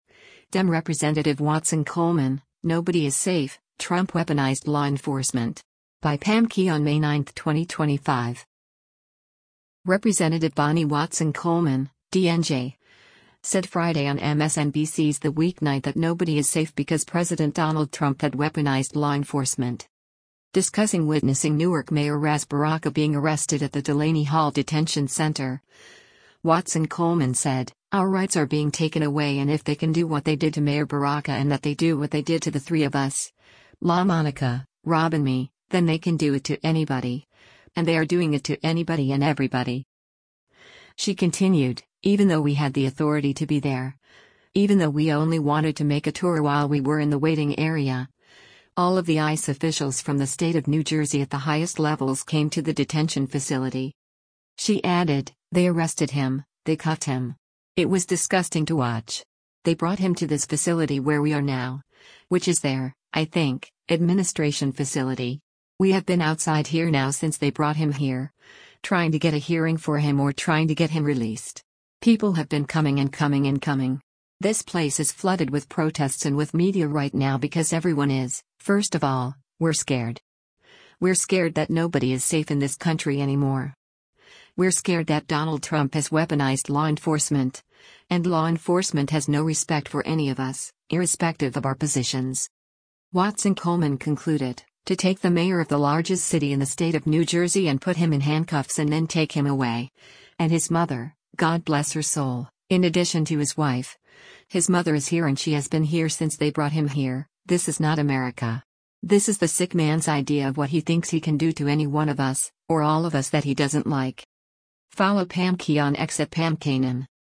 Representative Bonnie Watson Coleman (D-NJ) said Friday on MSNBC’s “The Weeknight” that “nobody is safe” because President Donald Trump had “weaponized law enforcement.”